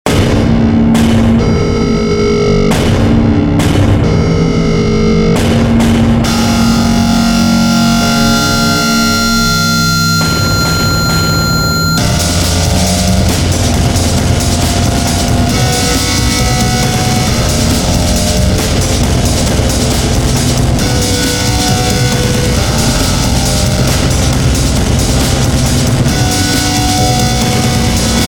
Trying out a style that is in-between ambient and drum&bass/jungle...
...with a noisy vibe.
This is way to heavy.